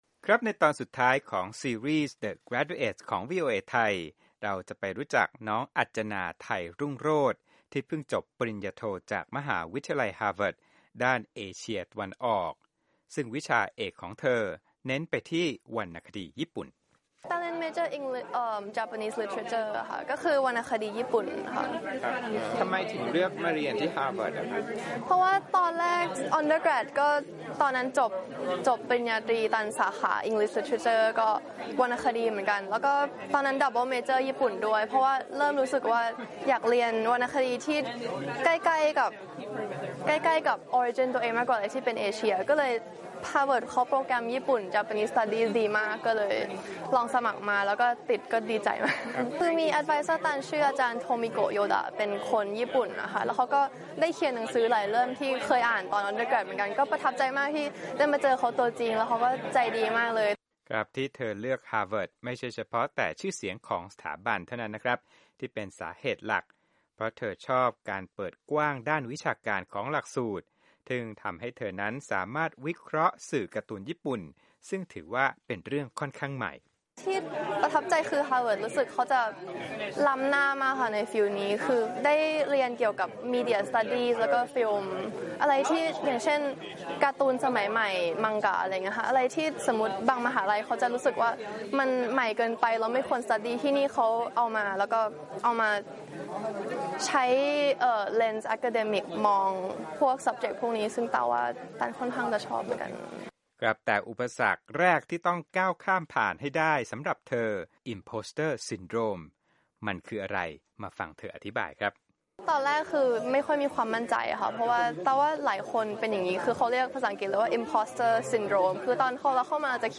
ในช่วงสองเดือนนี้เป็นฤดูรับปริญญาของนักเรียนนักศึกษาในสหรัฐ ซึ่งเป็นช่วงเดียวกับที่วีโอเอไทยทำรายงานด้านการศึกษาที่นครบอสตั้น